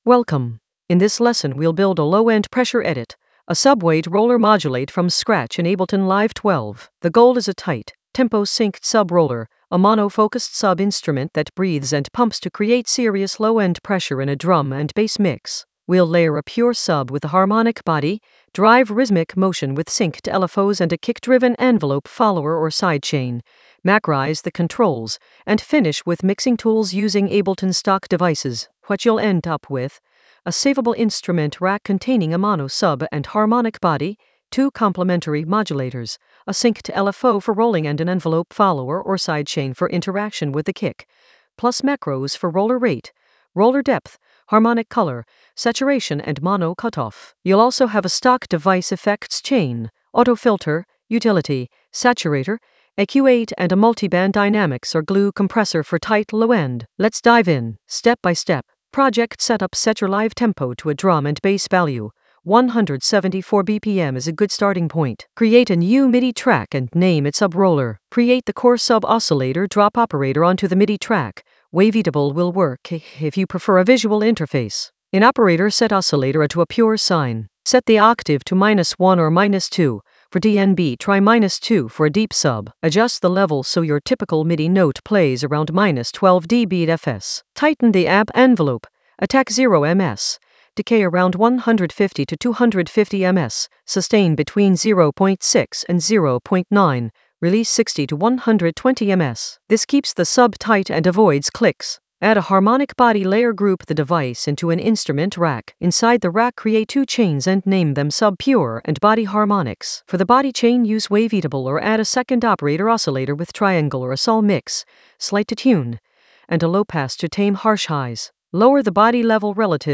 An AI-generated intermediate Ableton lesson focused on Low-End Pressure edit: a subweight roller modulate from scratch in Ableton Live 12 in the Workflow area of drum and bass production.
Narrated lesson audio
The voice track includes the tutorial plus extra teacher commentary.